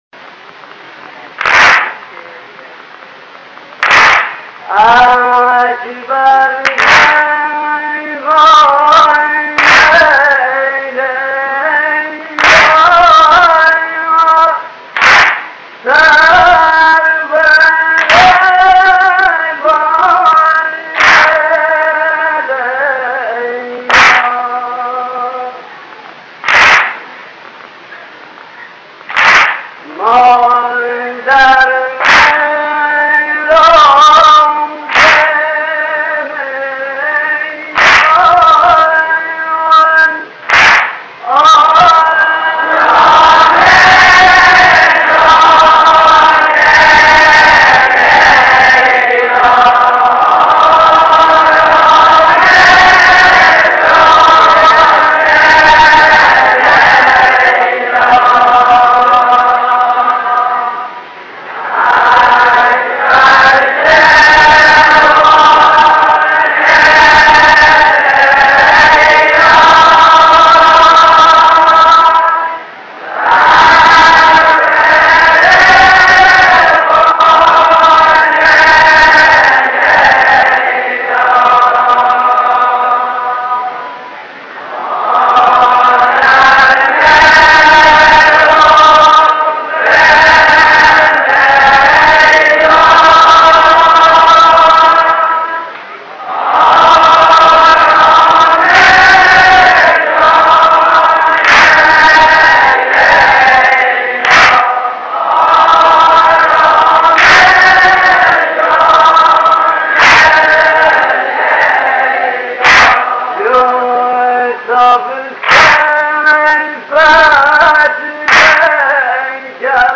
محرم-نوحه-اکبر-جوان-لیلا-دشتی.mp3